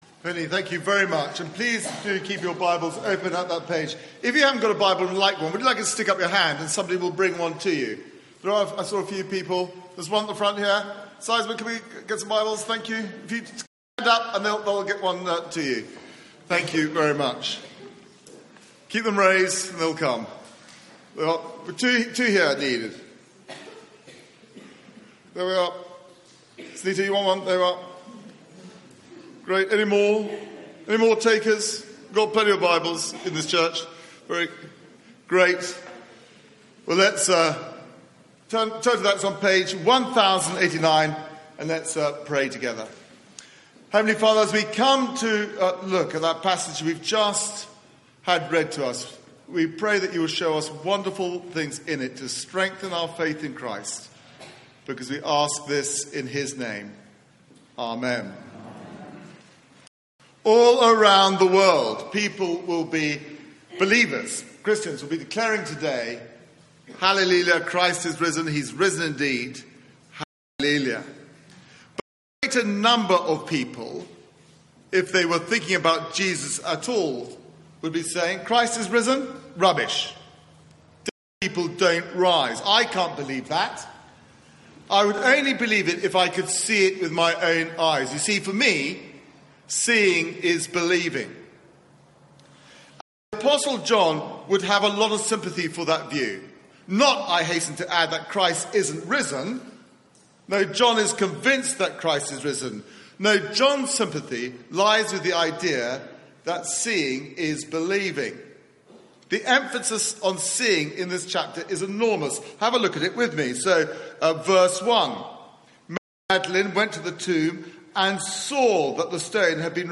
Media for 9:15am Service on Sun 01st Apr 2018 09:15 Speaker
Easter Sunday Sermon